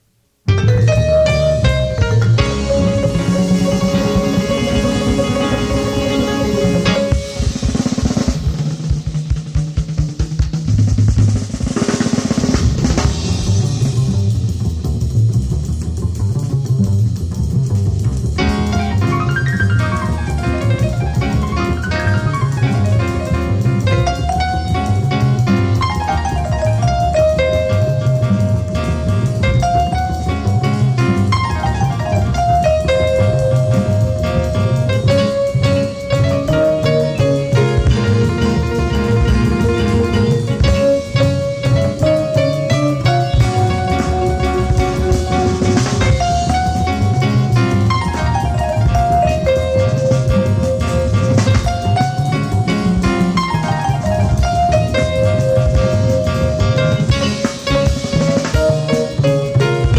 a drums and percussion-rich sextet
harmonica